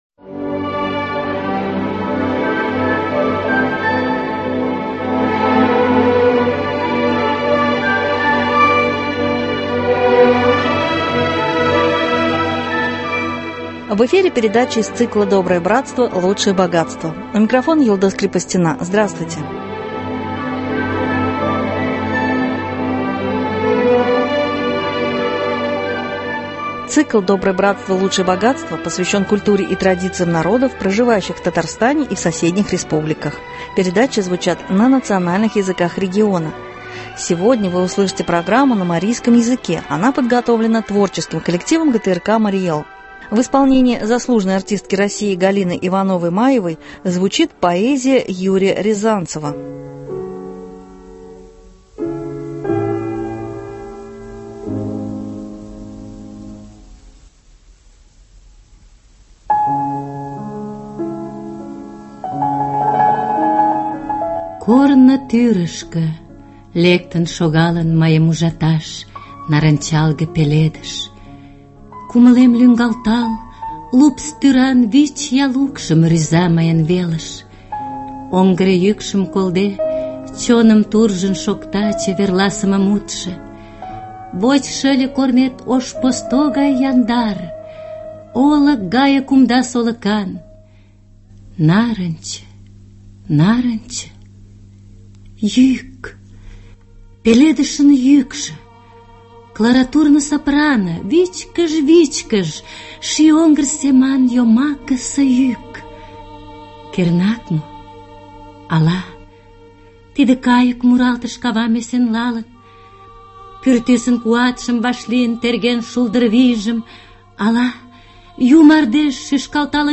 звучит поэзия Юрия Рязанцева